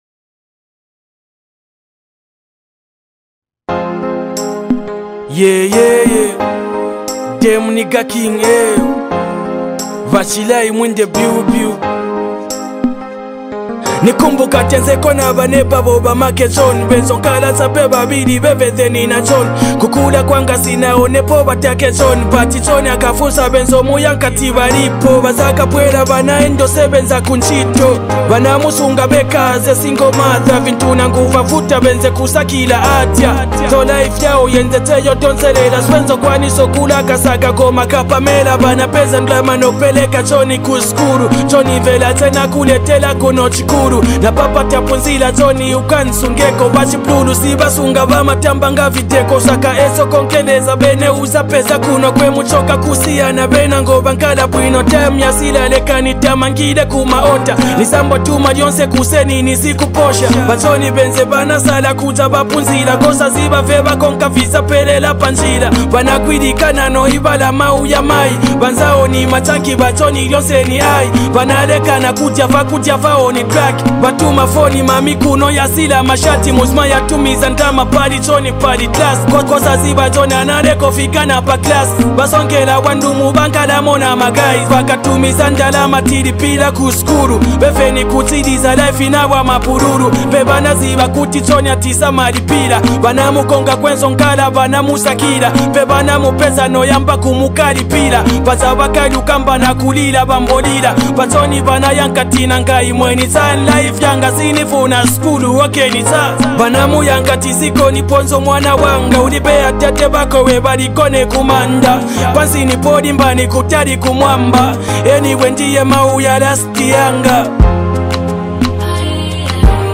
A Heartfelt Afro-Fusion Jam
” offering reflective verses wrapped in melodic rhythms.
smooth vocals and heartfelt delivery